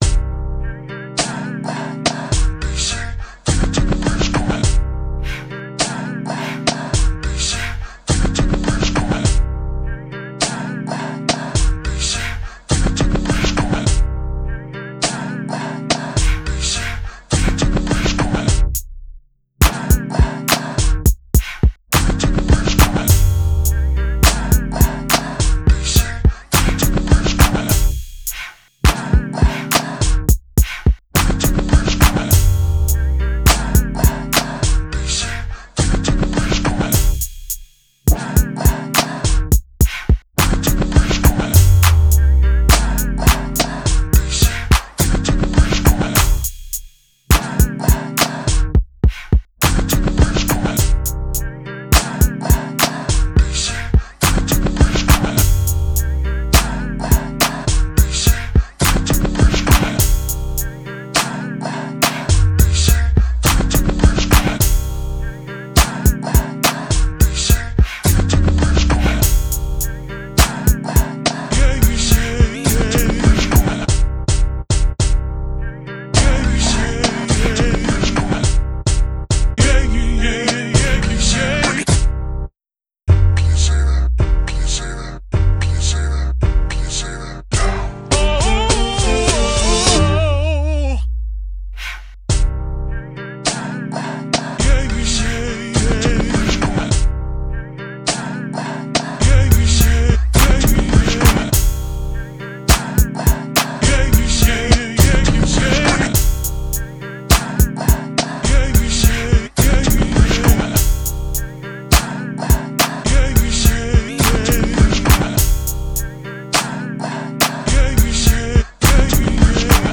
In this piece, I sample “Say My Name” by Destiny’s Child to define myself.